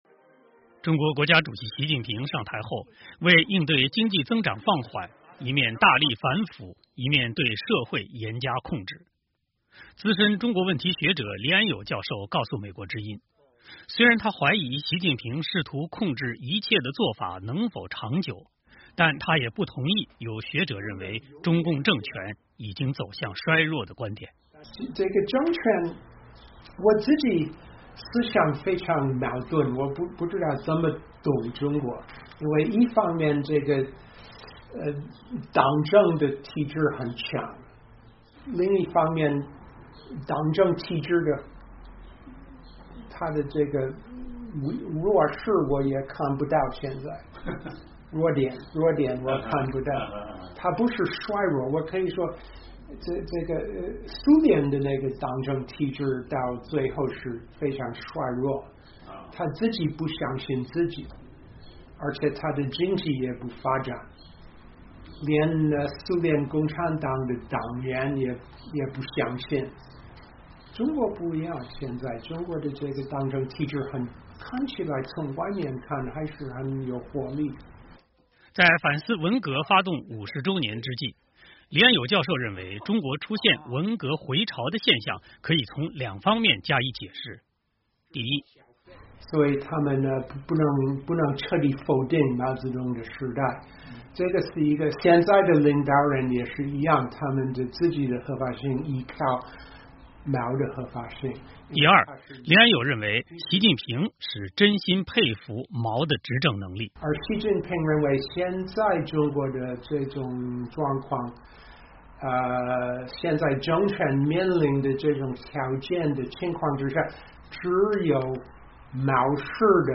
最近他在接受美国之音专访时指出，中共政权并不像有学者提出的已经开始走向衰弱。